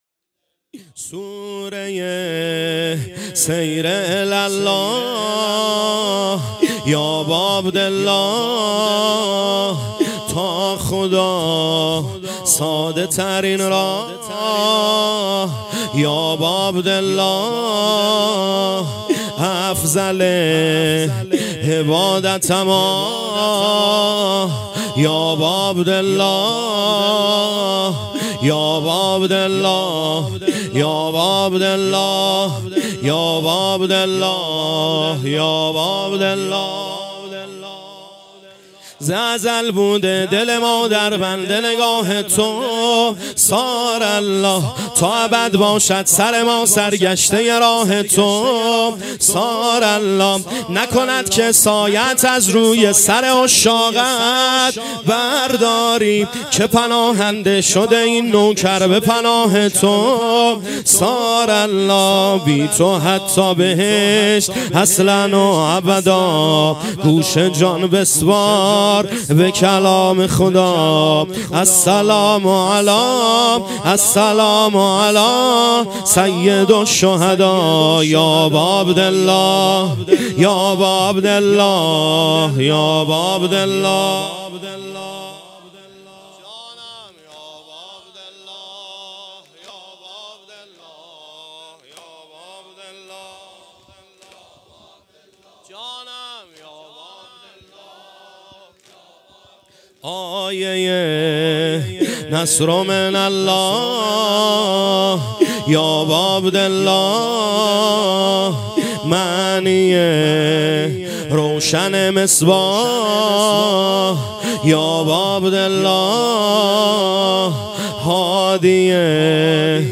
مراســم عـزادارى شـب چهارم محرّم
محرم 1402 - شب چهارم